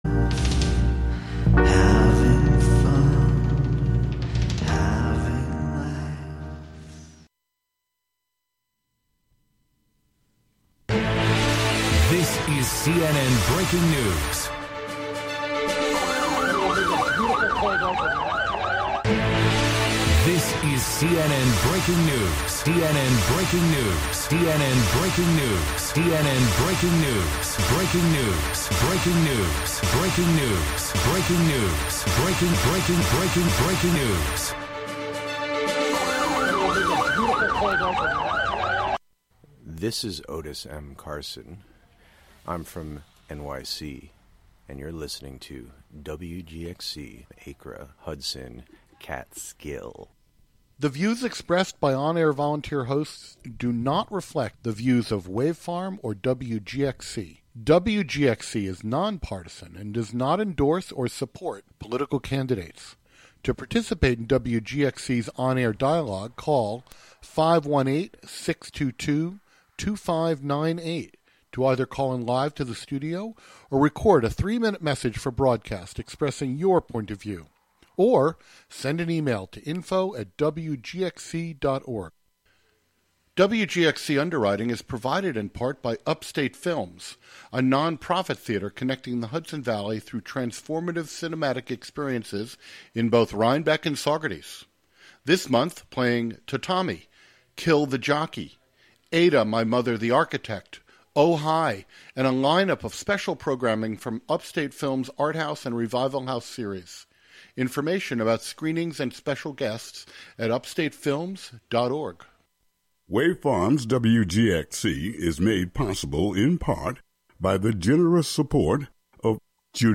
What started as a photography series then video series, "Uncontaminated Sound" is a conversation series for radio that follows comedians, musicians, fine artists, and actors with the primary objective to gain a particular insight into their creative processes which can only be fully unravelled by truly going behind the scenes.
These features offer listeners real, raw, and authentic conversations.